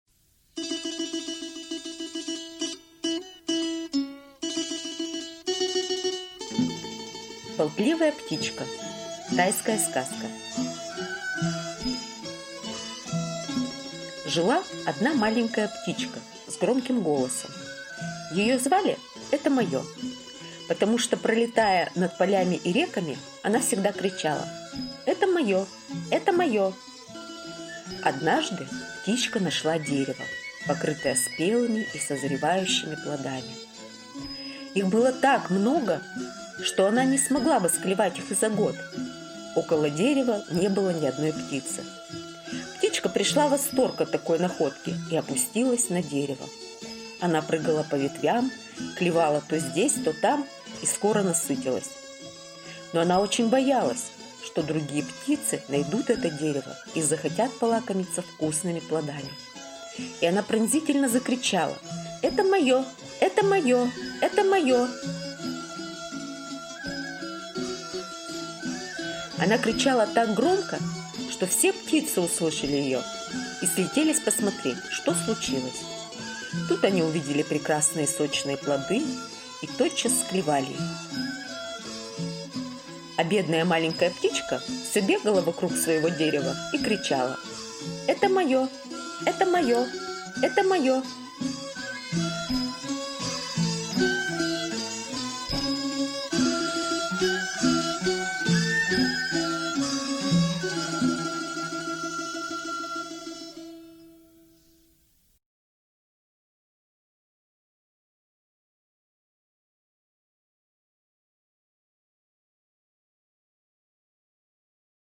Болтливая птичка - тайская аудиосказка - слушать онлайн